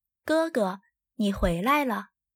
Gēgē nǐ huílái le
グァグァ ニー フゥイライラ